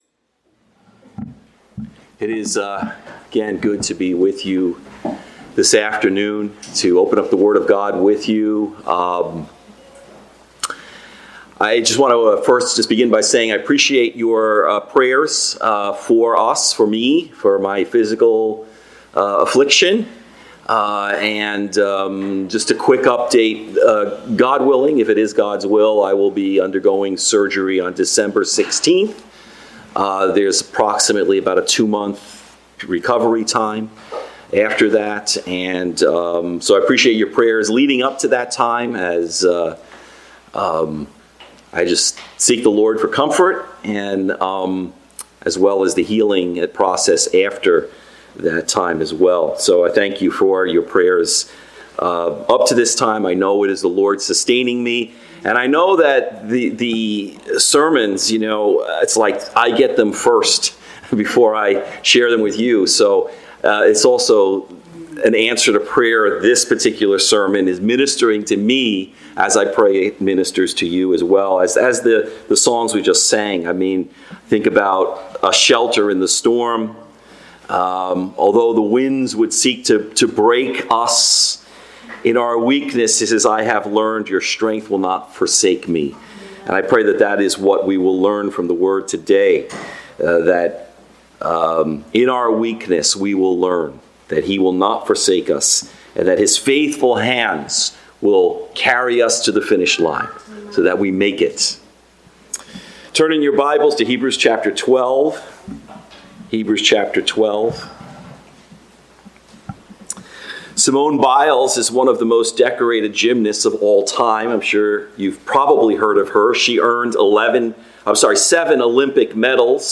The Good News About Discipline | SermonAudio Broadcaster is Live View the Live Stream Share this sermon Disabled by adblocker Copy URL Copied!